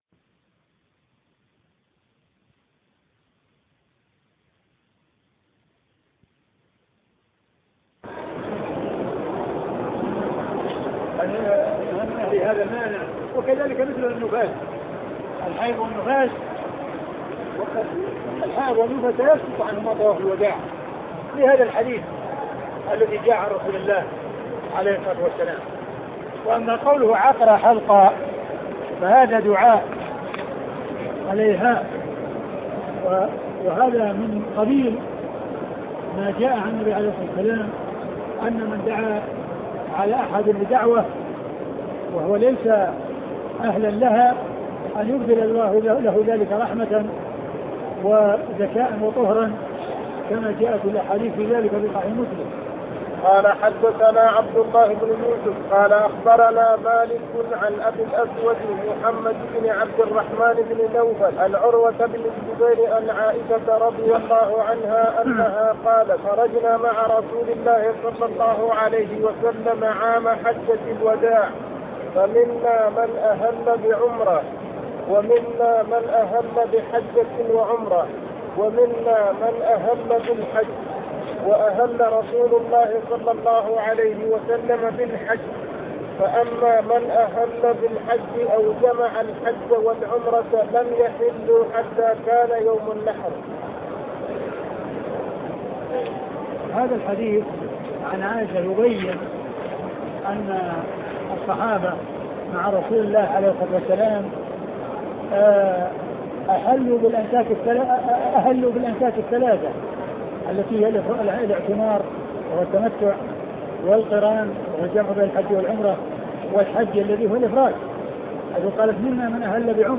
صحيح البخاري شرح الشيخ عبد المحسن بن حمد العباد الدرس 193